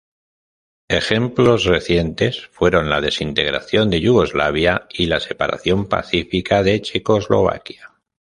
se‧pa‧ra‧ción
/sepaɾaˈθjon/